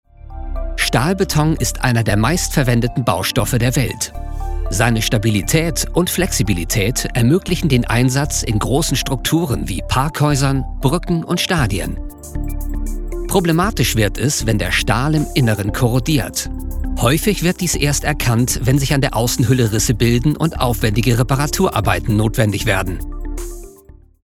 Commercieel, Stoer, Volwassen, Vriendelijk, Zakelijk
Explainer